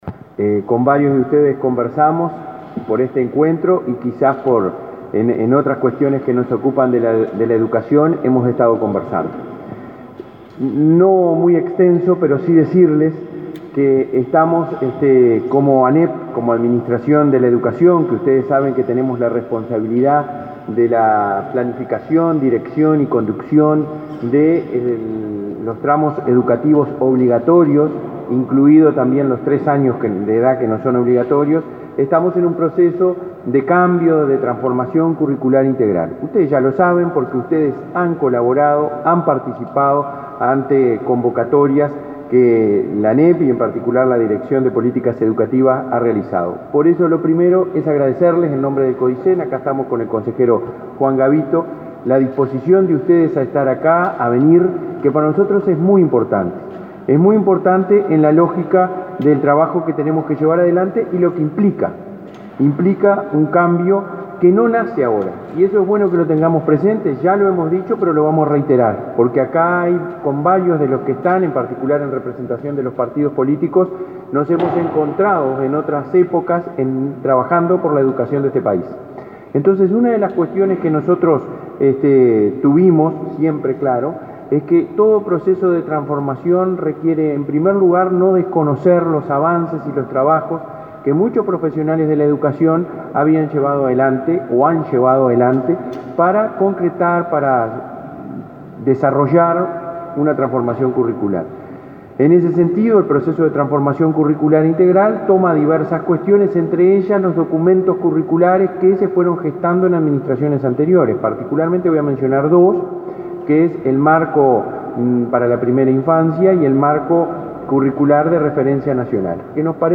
Palabras del presidente de la ANEP, Robert Silva
Este jueves 2, las autoridades de la educación, encabezadas por el presidente de la ANEP, Robert Silva, se reunieron con actores políticos con representación parlamentaria y referentes del ámbito social-productivo con el objetivo de dialogar y reflexionar sobre el documento preliminar en proceso de elaboración y consulta "Marco curricular nacional”.